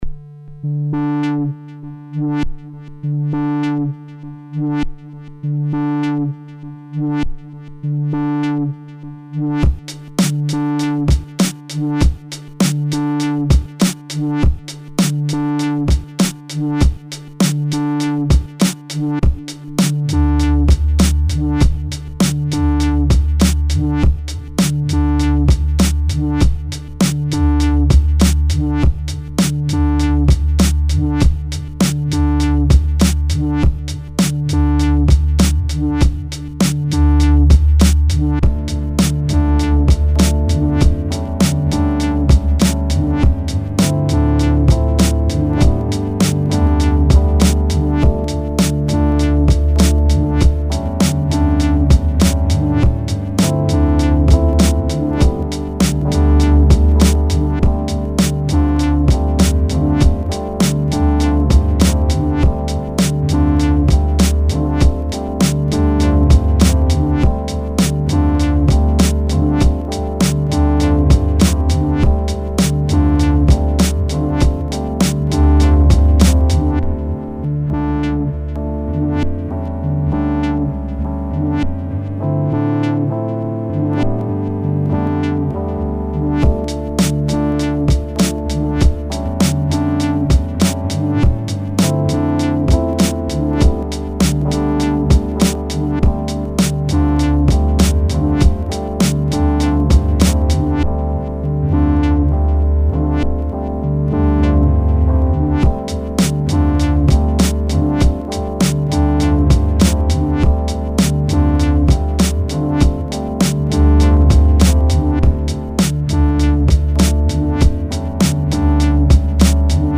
[국내 / PROD.]